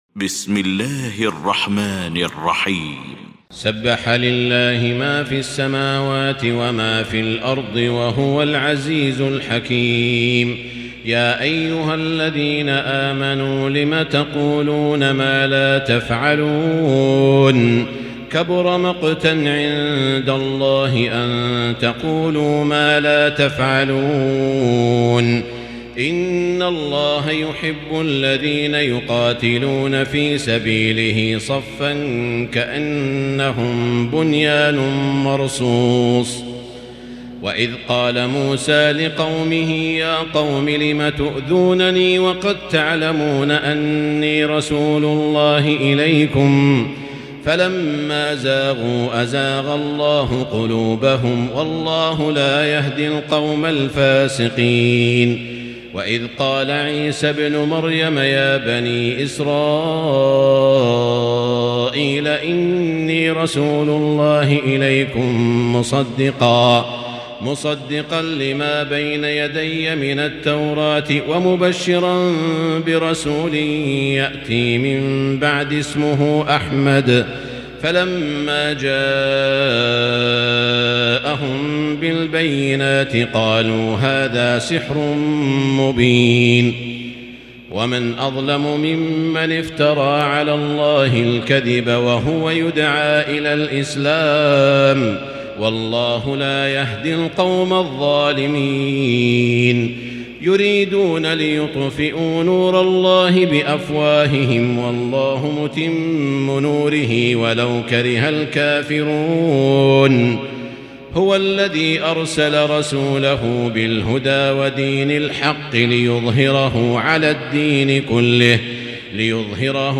المكان: المسجد الحرام الشيخ: سعود الشريم سعود الشريم الصف